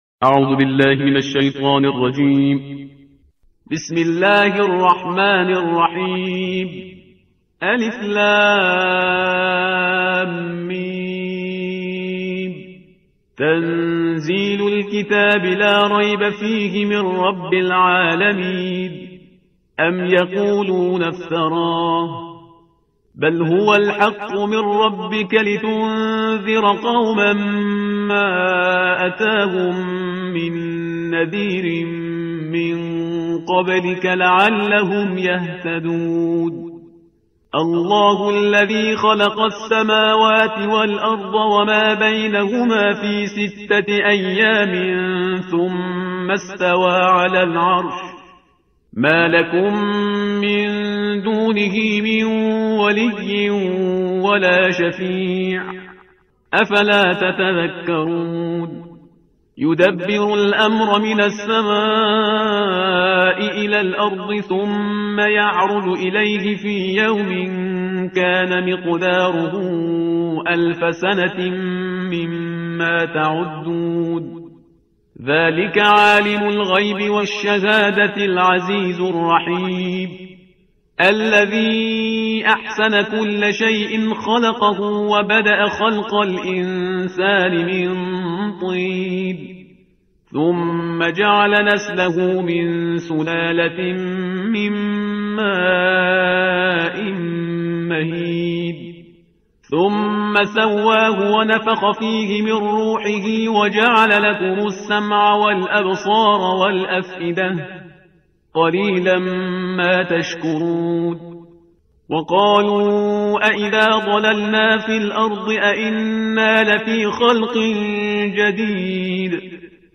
ترتیل صفحه 415 قرآن – جزء بیست و یکم